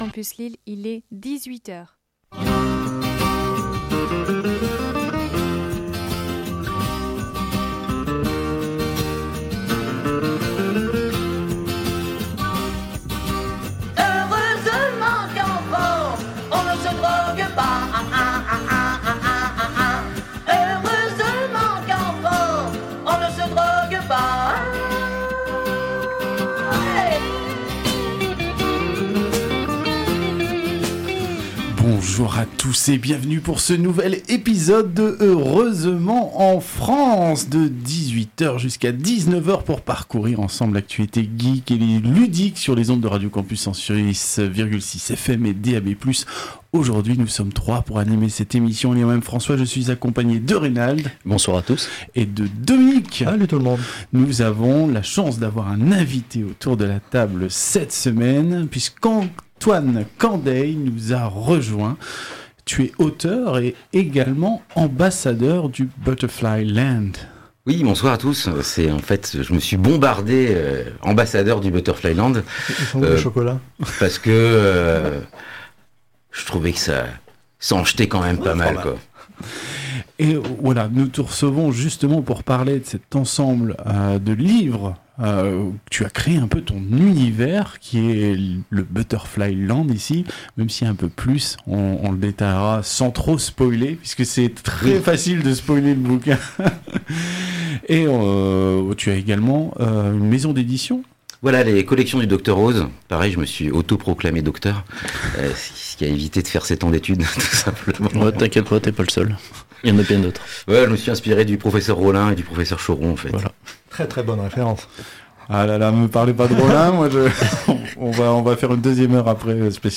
Au sommaire de cet épisode diffusé le 06 Novembre 2022 sur Radio Campus 106,6 :